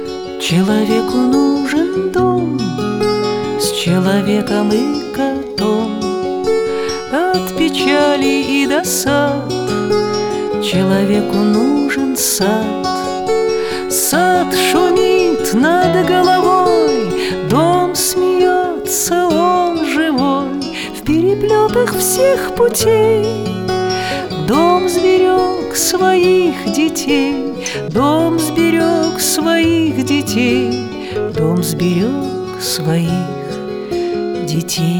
Жанр: Русская поп-музыка / Русский рок / Русские